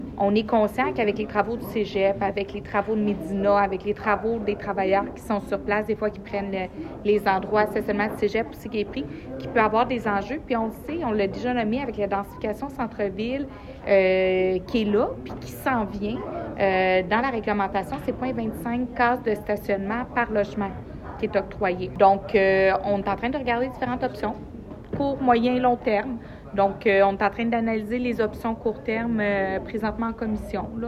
À la séance du conseil municipal de Granby hier soir